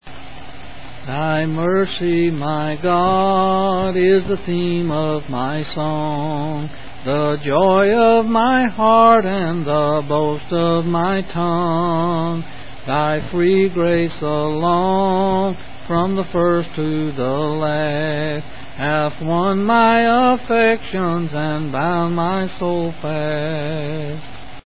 11s